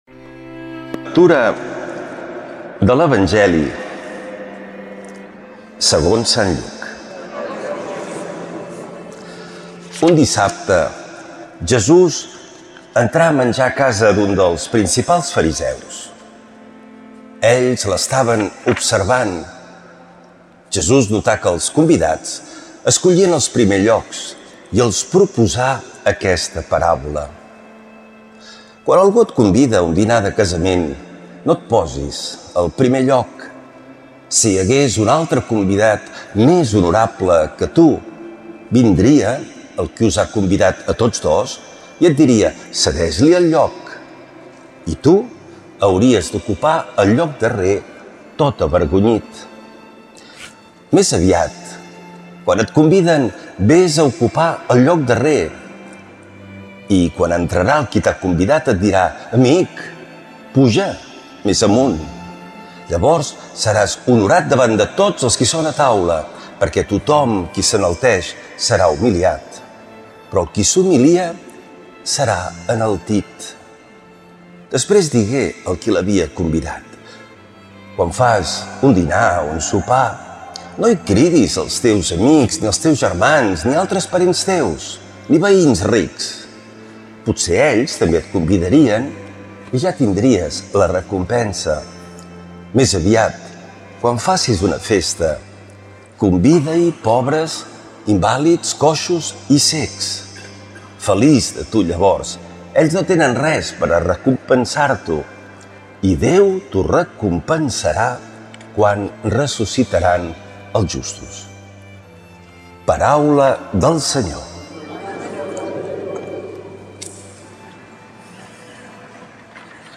L’Evangeli i el comentari de diumenge 31 d’agost del 2025.
Lectura de l’evangeli segons sant Lluc